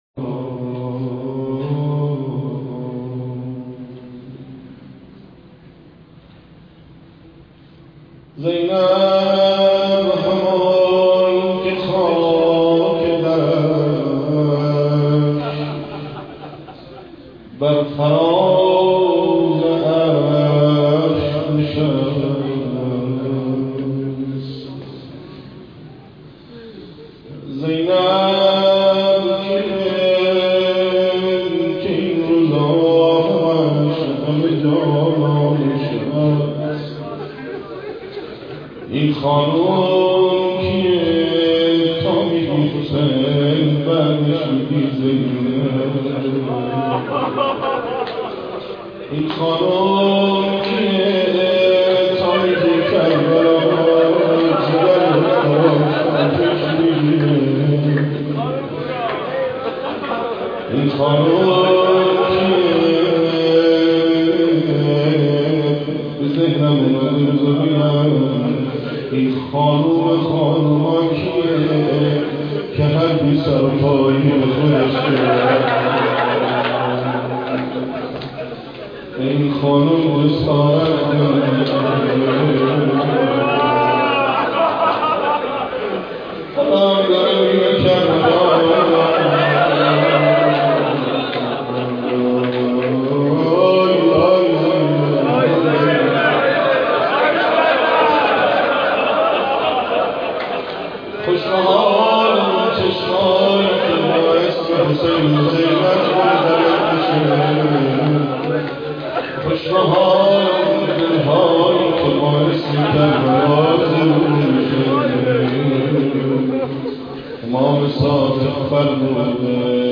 مداحی
عقیق : مراسم عزاداری شب 19 صفر با حضور جمعی از دلدادگان آل الله در هیئت آل یاسین واقع در حسینیه شهید همت میدان شهدا برگزار شد .